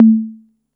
TR 808 Conga 01.wav